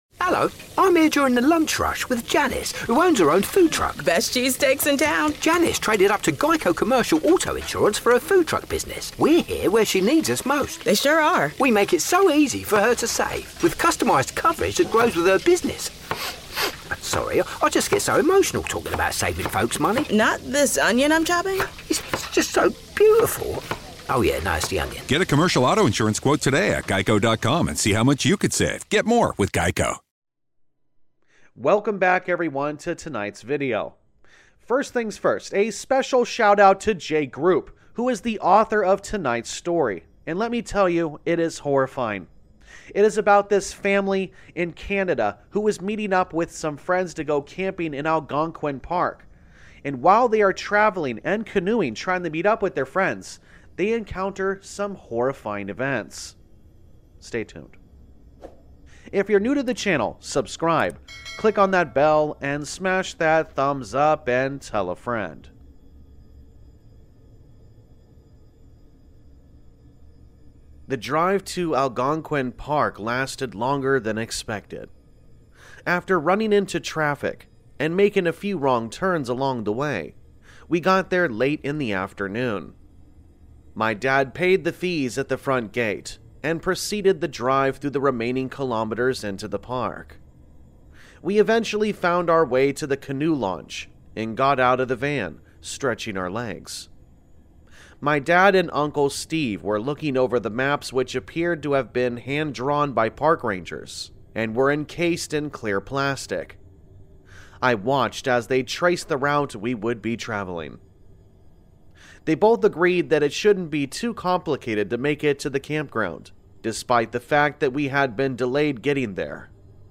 Campfire Tales is a channel which is focused on Allegedly True Scary Stories and Creepypastas.